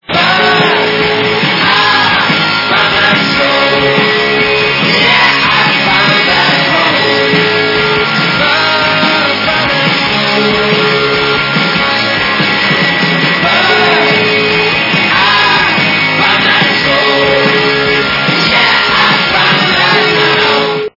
зхідна естрада
При заказе вы получаете реалтон без искажений.